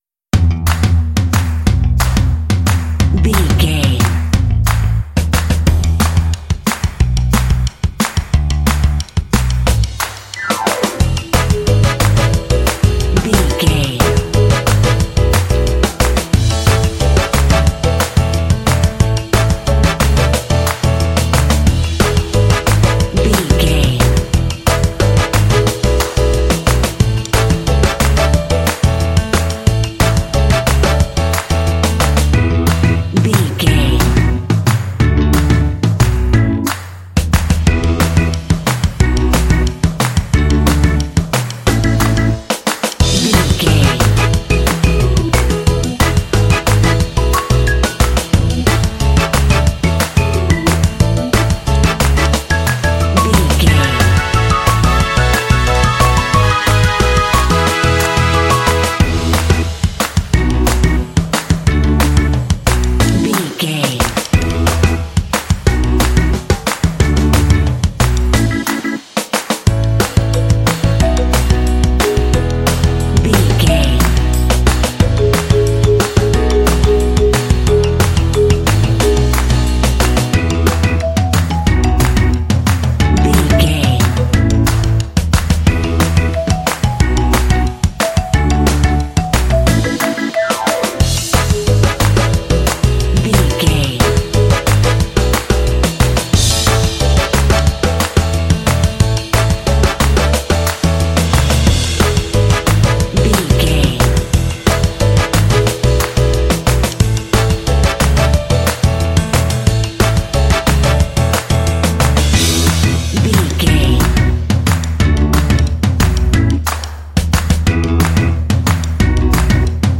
Ionian/Major
Fast
cheerful/happy
lively
energetic
playful
drums
bass guitar
piano
brass
electric organ
alternative rock